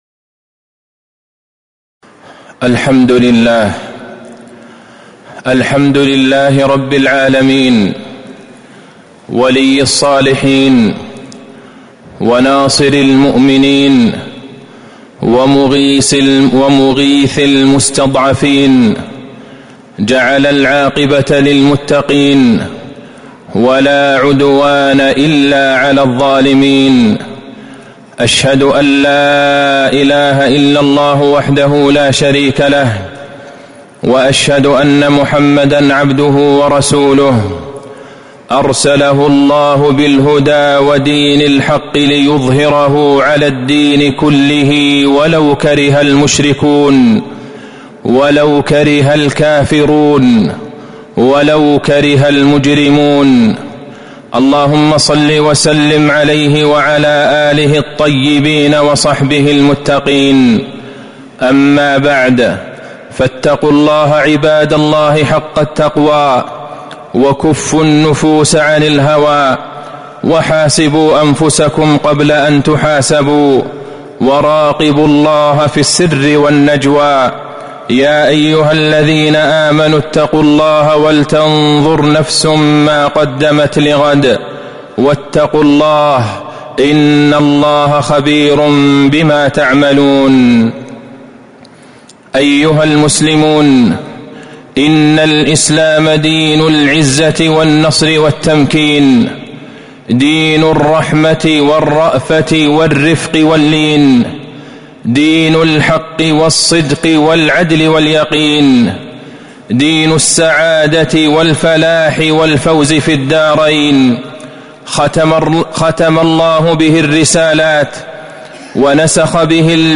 تاريخ النشر ٥ ربيع الثاني ١٤٤٥ هـ المكان: المسجد النبوي الشيخ: فضيلة الشيخ د. عبدالله بن عبدالرحمن البعيجان فضيلة الشيخ د. عبدالله بن عبدالرحمن البعيجان ألا إن نصر الله قريب